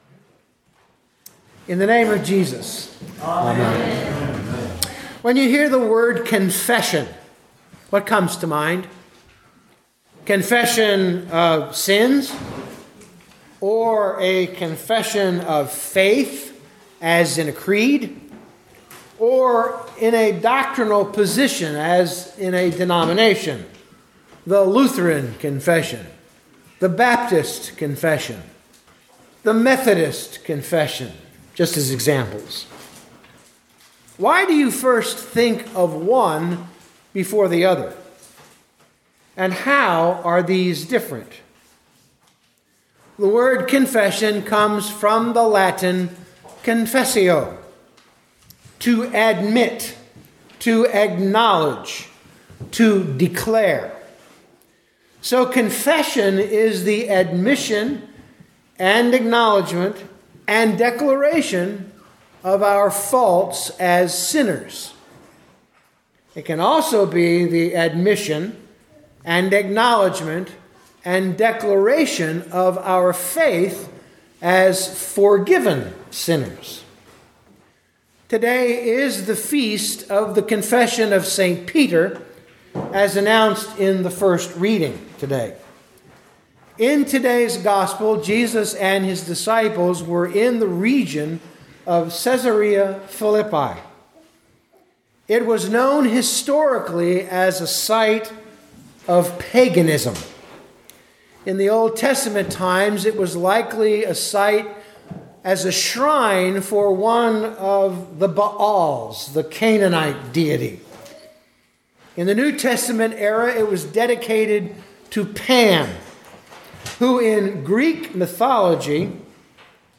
Acts 4:8-13 Listen to the sermon with the player below, or, download the audio.